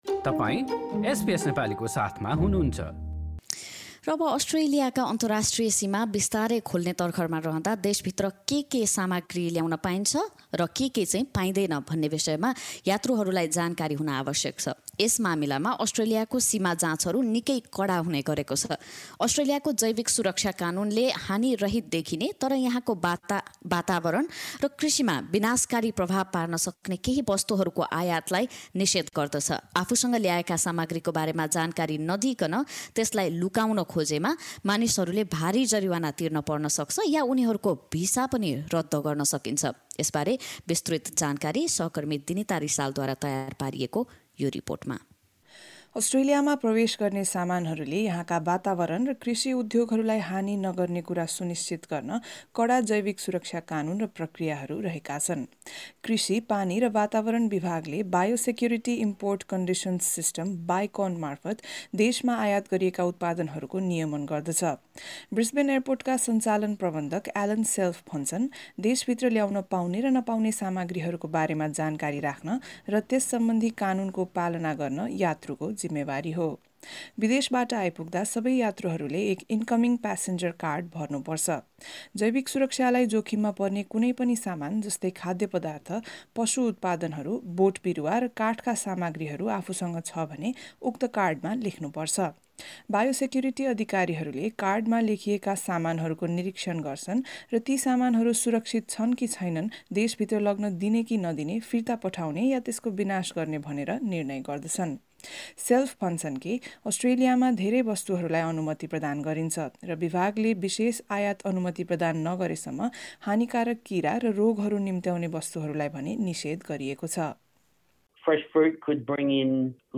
रिपोर्ट सुन्नुहोस: null हाम्रा थप अडियो प्रस्तुतिहरू पोडकास्टका रूपमा उपलब्ध छन्।